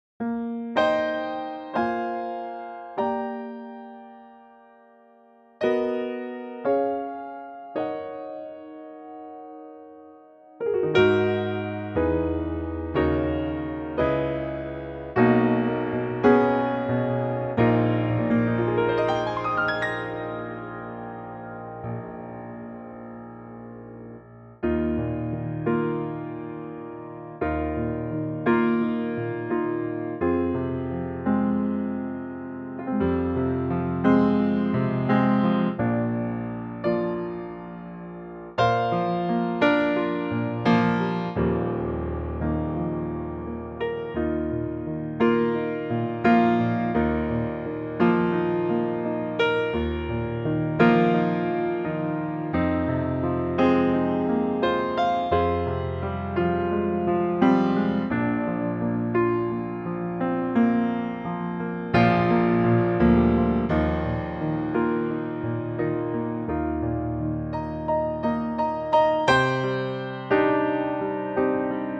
Unique Backing Tracks
key - Eb - vocal range - Eb to Bb
Very lovely piano only arrangement